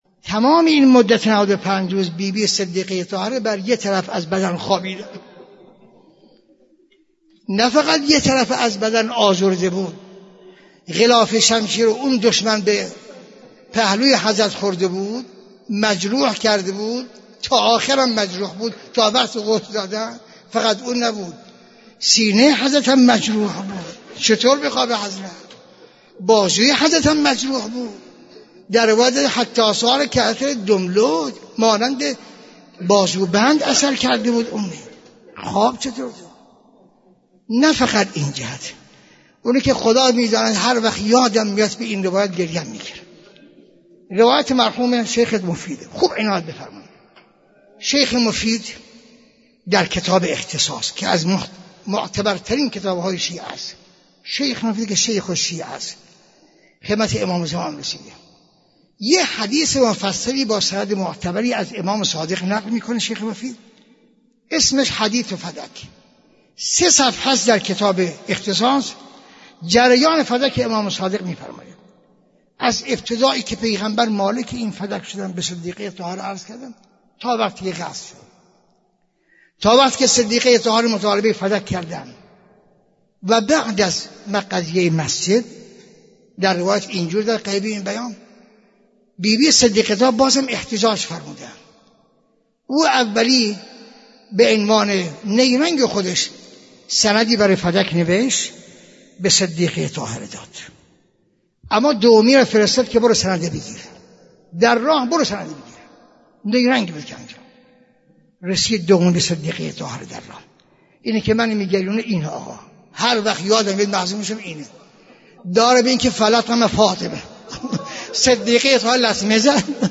دانلود مداحی کوچه بنی هاشم - دانلود ریمیکس و آهنگ جدید